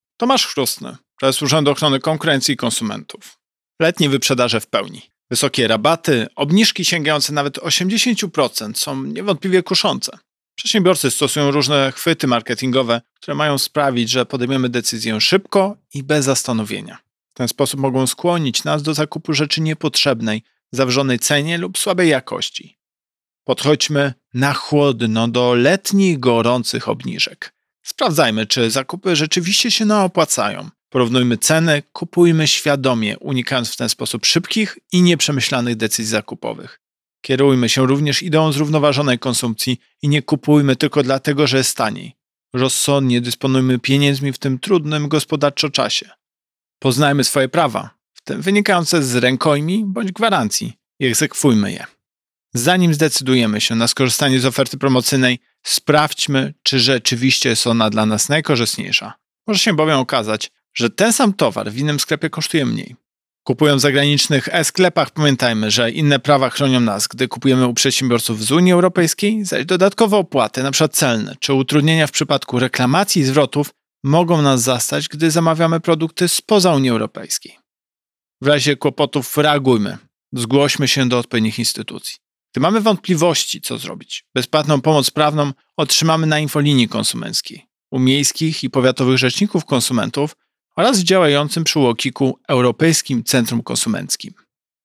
Pobierz wypowiedź Prezesa UOKiK Tomasza Chróstnego "Podchodźmy „na chłodno” do letnich, gorących obniżek.